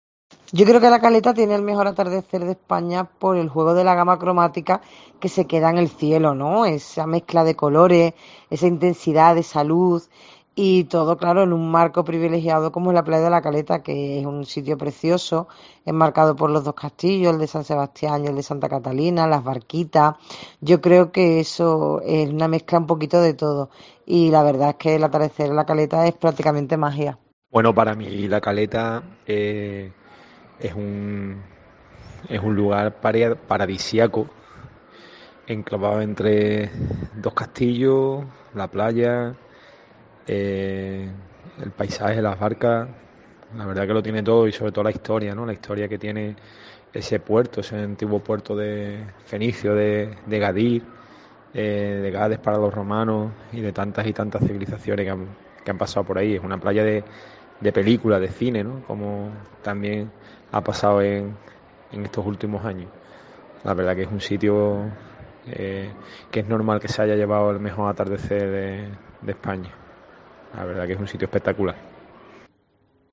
Los gaditanos opinan sobre el atardecer en La Caleta